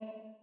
hard_drop.wav